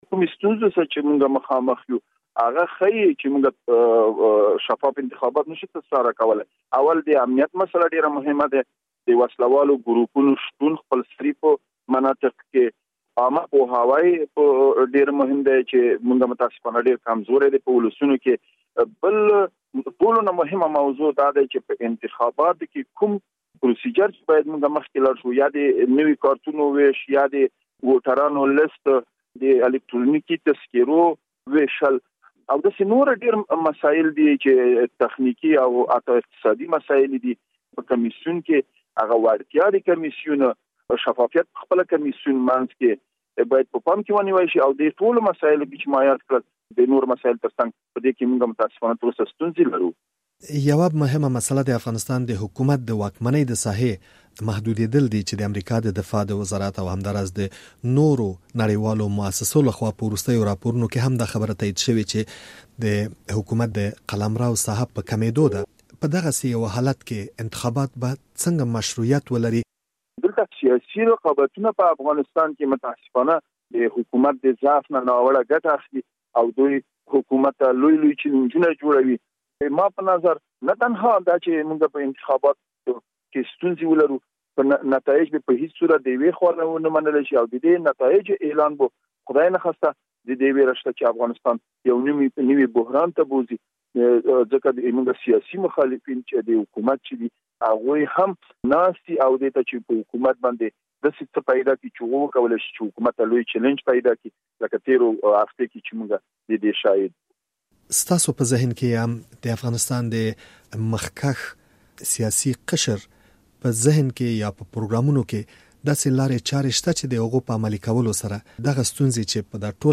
له ګل پاچا مجیدي سره مرکه